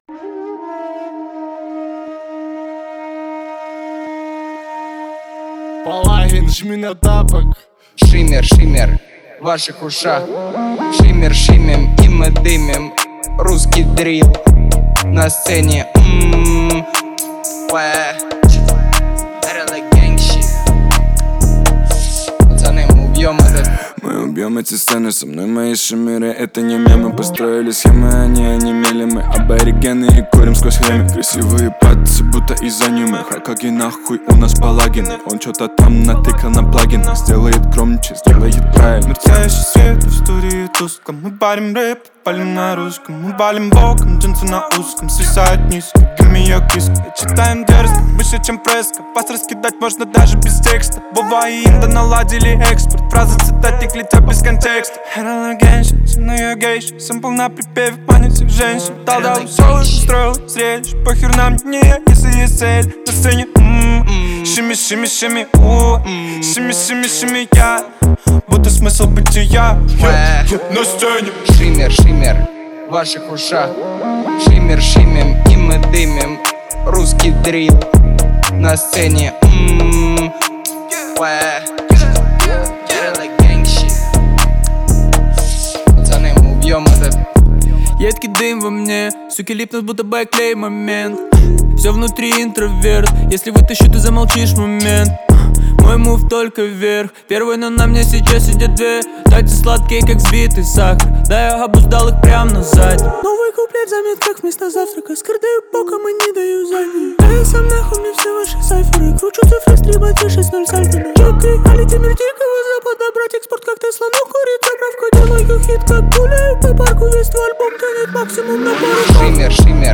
это яркий пример современного хип-хопа